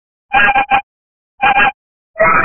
Cat Sound Effects Free Download